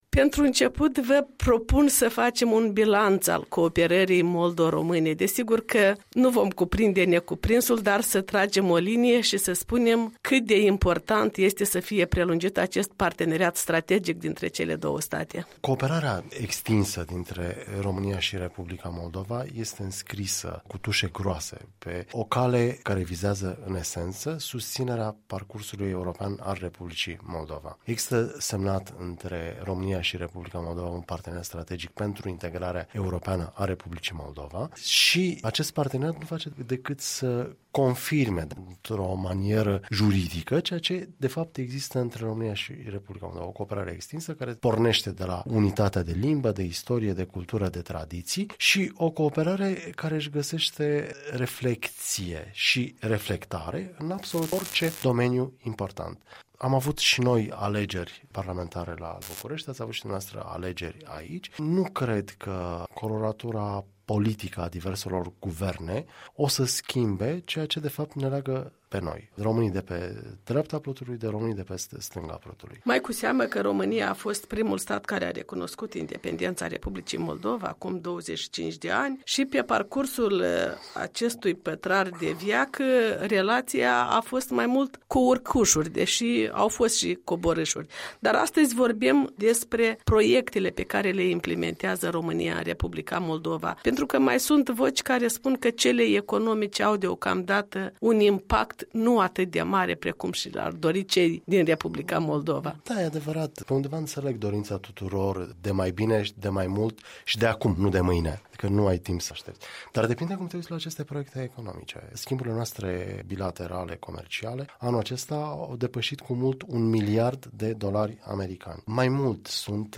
Un interviu cu ambasadorul României la Chișinău.